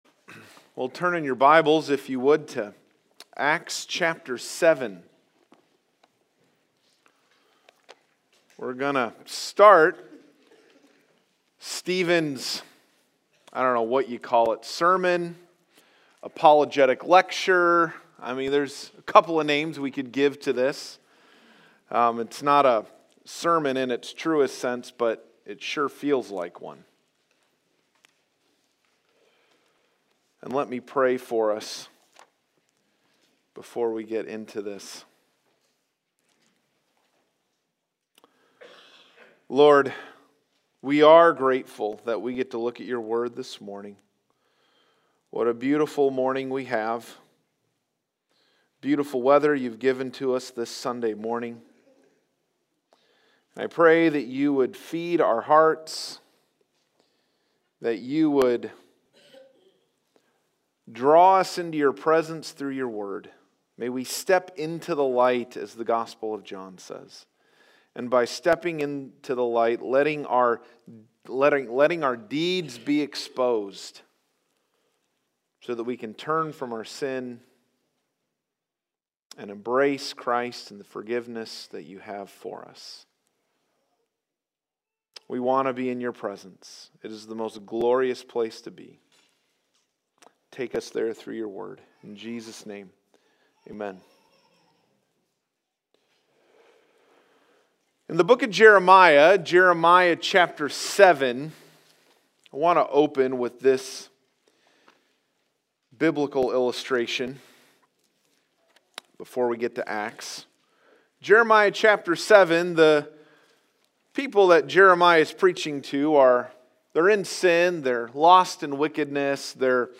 Stephen’s Martyrdom Sermon